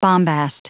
Prononciation du mot bombast en anglais (fichier audio)
Prononciation du mot : bombast